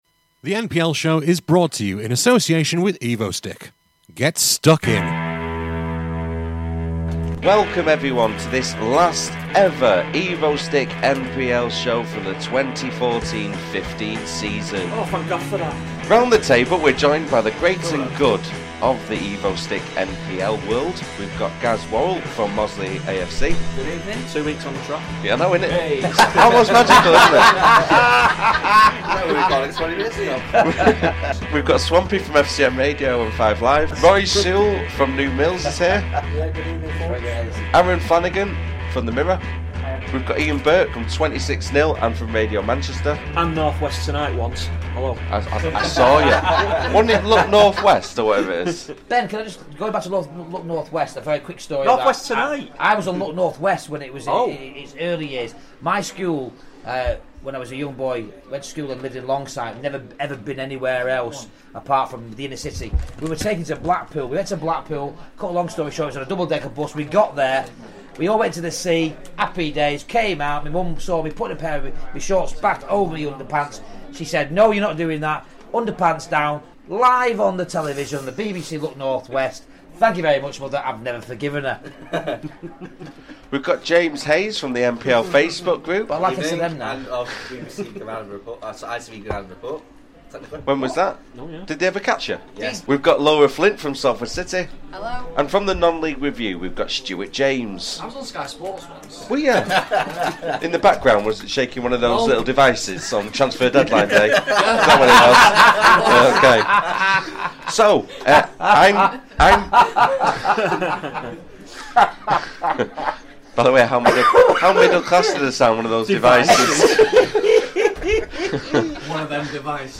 This programme was recorded at the Magnet in Stockport on Wednesday 29th April 2015.